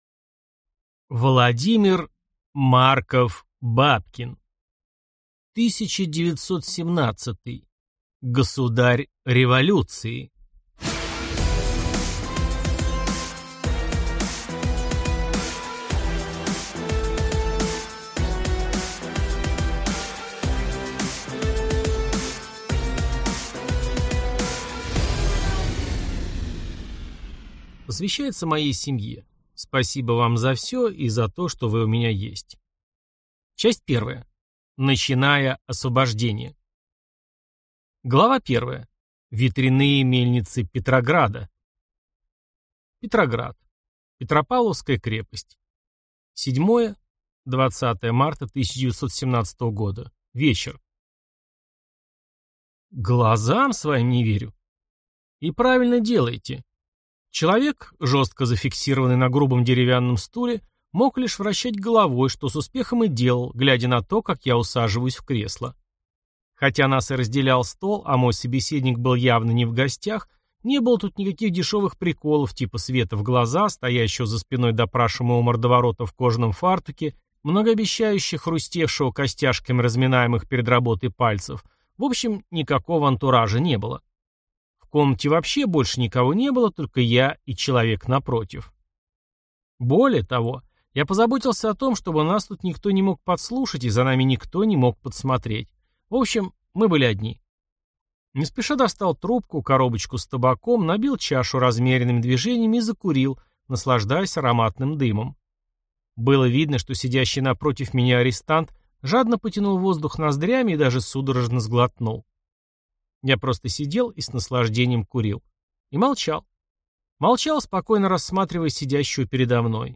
Аудиокнига 1917: Государь революции | Библиотека аудиокниг